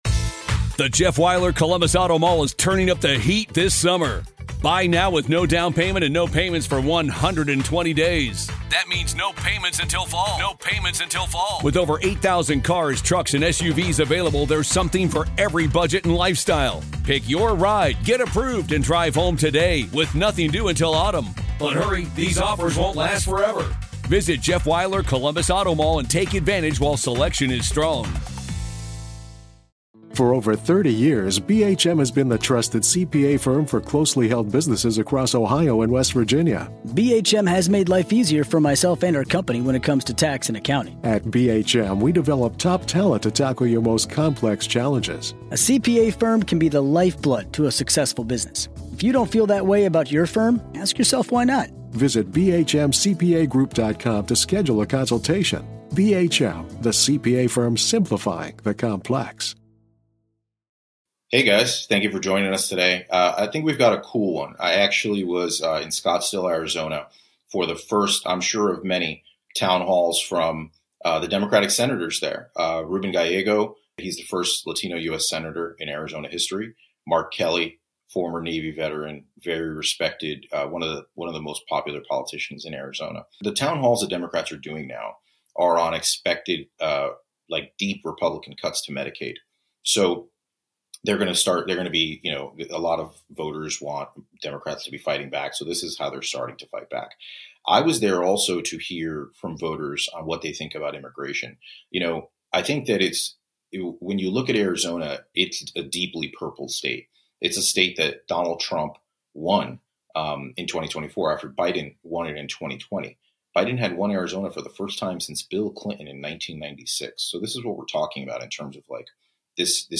Hear directly from Arizona locals on what matters to them concerning the new Trump Immigration plans.